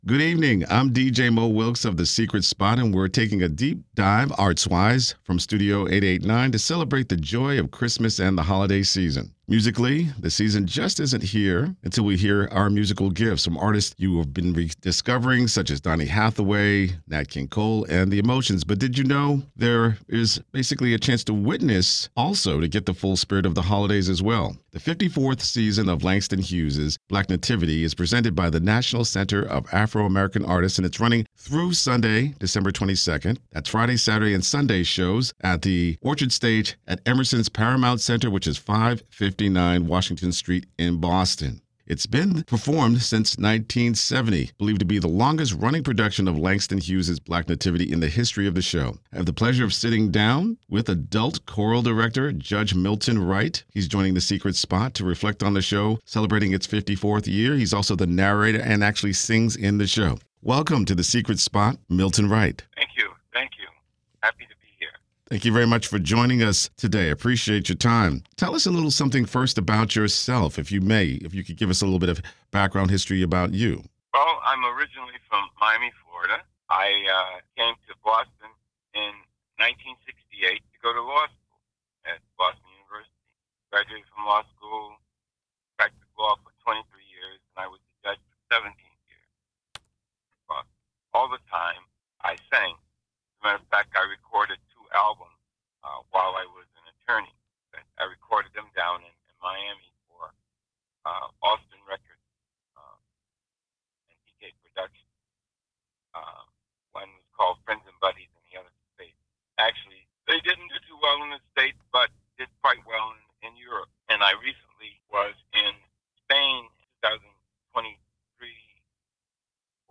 (Below is a transcript of some of the interview) Good evening.